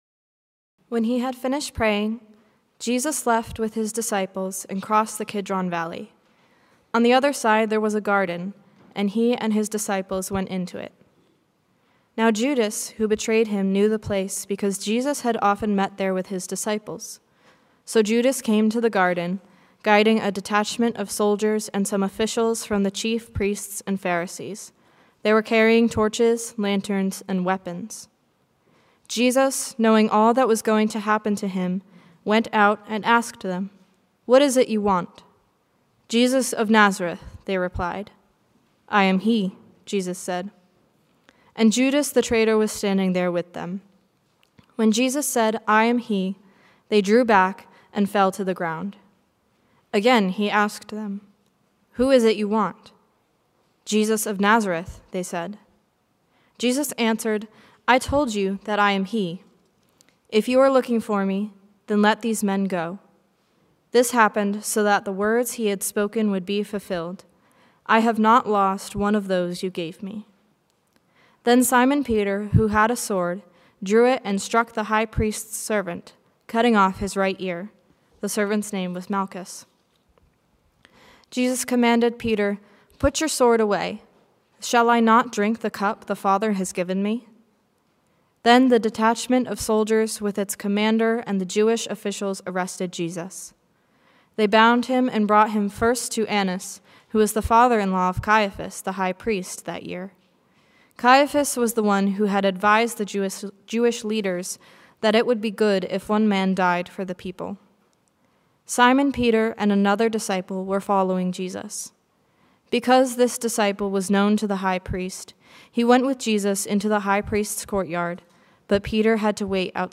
Sermon Discussion - 10.26.25 Bulletin - 10.26.25